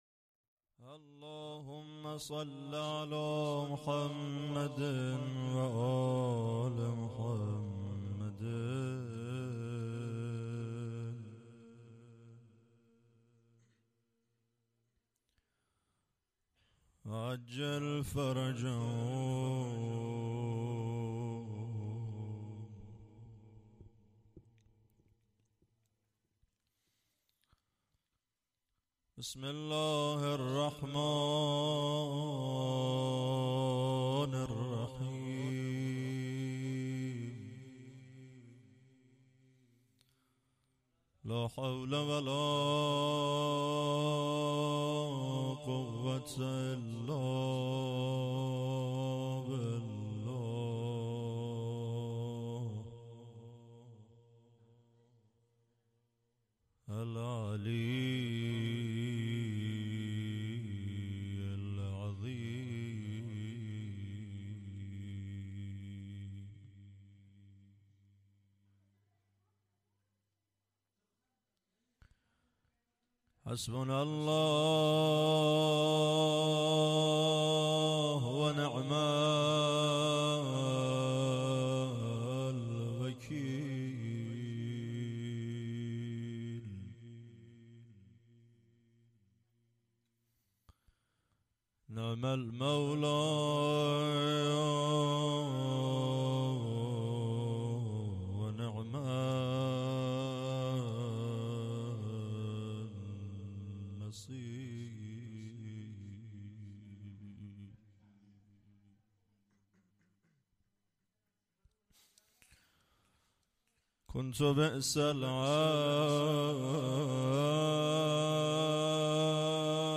مراسم مناجات خوانی و ذکر توسل ماه شعبان 16 اسفند ماه